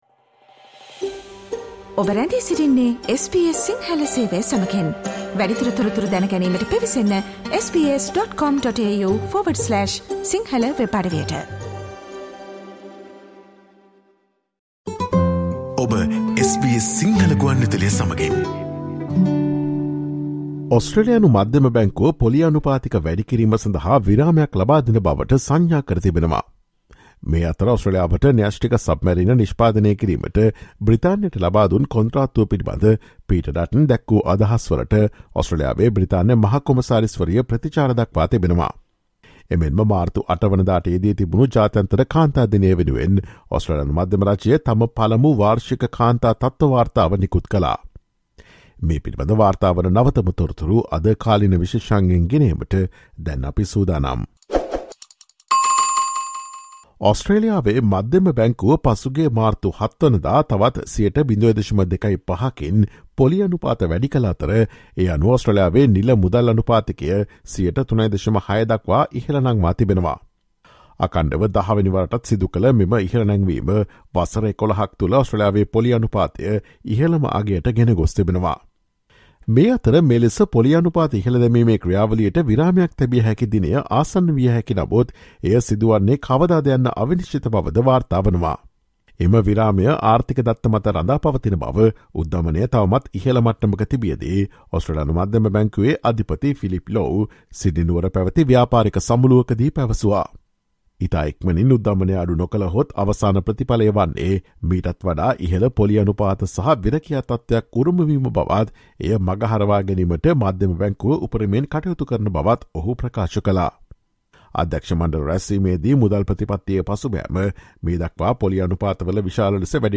Reserve Bank Governor Philip Lowe says the bank might be able to pause interest rate hikes depending on its assessment of incoming economic data. British High Commissioner to Australia has responded to Peter Dutton's comments on the AUKUS [[aw-kass]] alliance. The Australian government released its first annual Status of Women Report card on International Women's Day. Listen to the SBS Sinhala Radio's current affairs feature on Thursday, 09 March 2023.